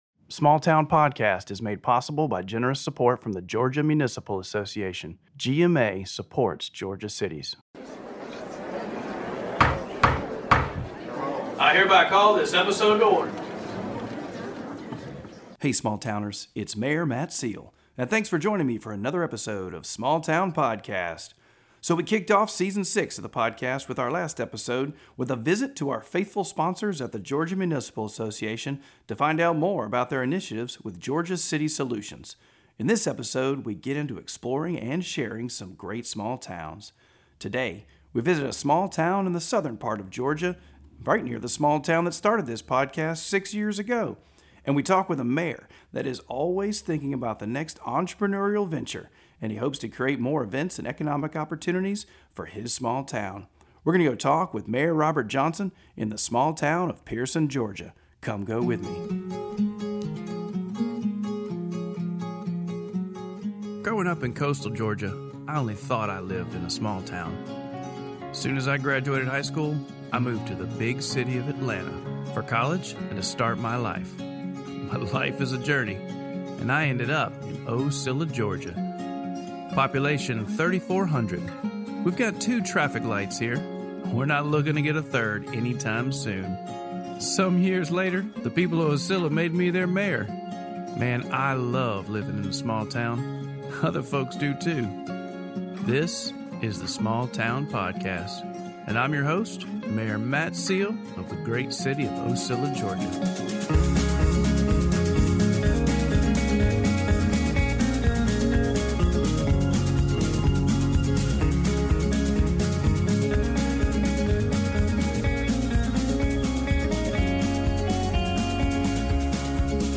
Facebook Twitter Headliner Embed Embed Code See more options Small Town Podcast Host Mayor Matt Seale travels to Pearson, Georgia, a small town in the southern part of the state, right near the small town that started this podcast six years ago! Come with us to talk to Mayor Robert Johnson, a mayor who is always thinking about the next entrepreneurial adventure and creating more events and economical opportunities for his small town.